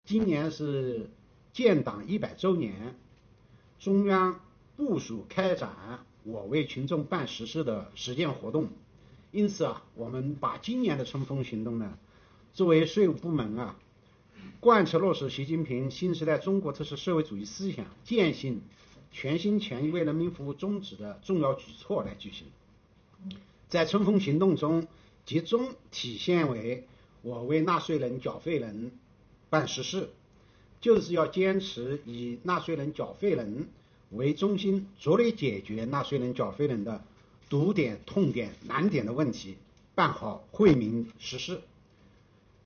2月24日，国家税务总局举行新闻发布会，启动2021年“我为纳税人缴费人办实事暨便民办税春风行动”。与往年相比，今年的“春风行动”主题和标题中突出了“我为纳税人缴费人办实事”，为啥这么改？国家税务总局党委委员、副局长任荣发为您权威解读。